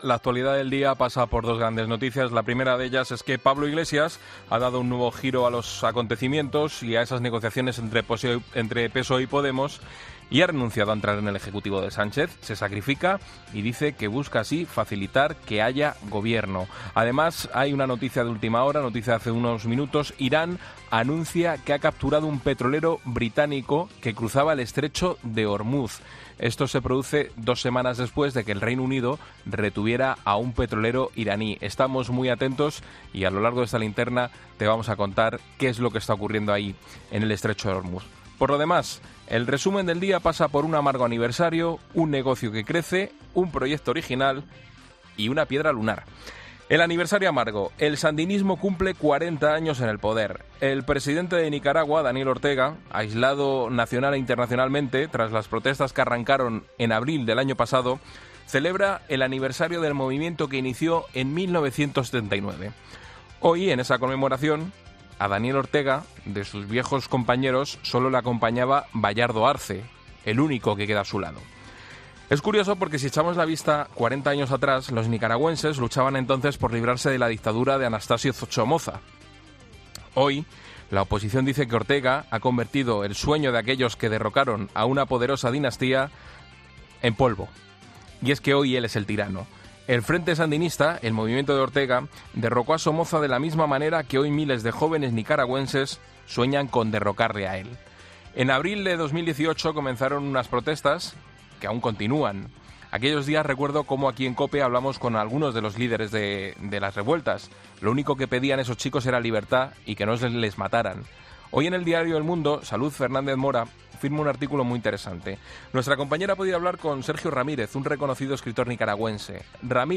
Boletín de noticias de COPE del 19 de julio de 2019 a las 21.00 horas